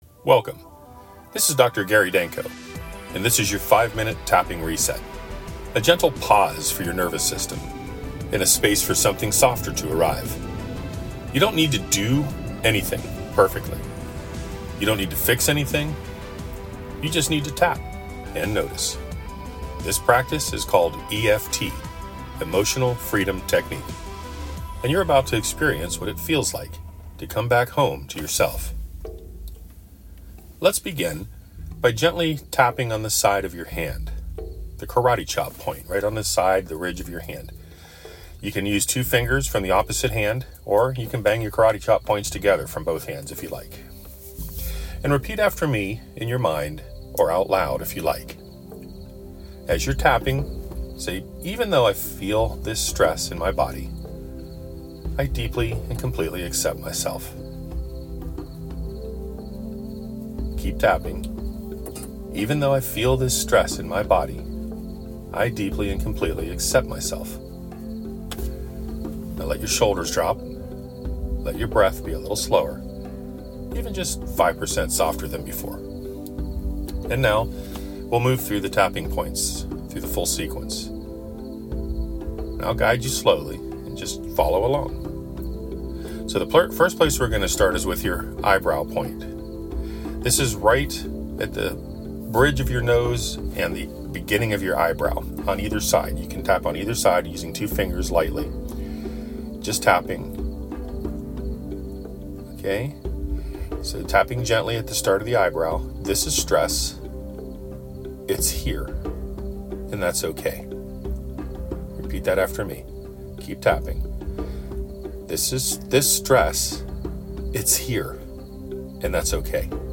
If you’ve been feeling stressed, anxious, or overwhelmed, this free guided EFT tapping session is a simple and powerful way to reset your nervous system in just a few minutes.